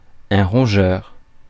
Ääntäminen
IPA: [ʁɔ̃.ʒœʁ]